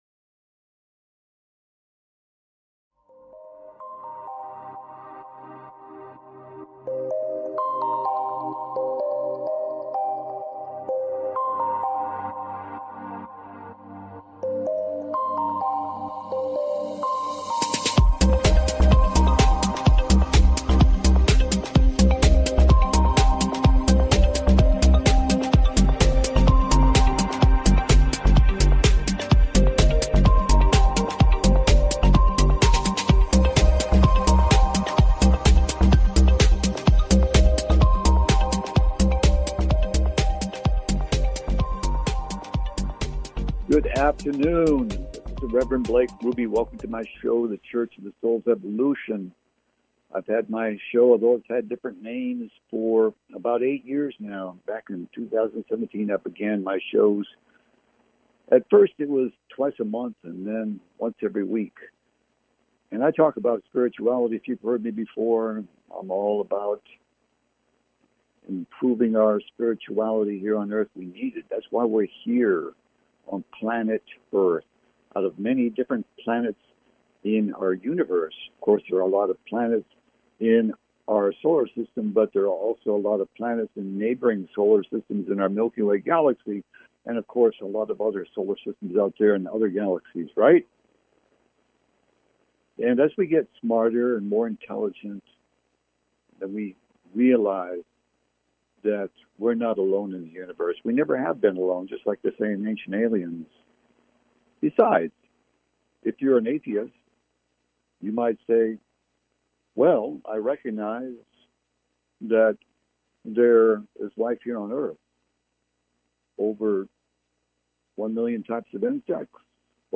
The Church of the Souls Evolution Talk Show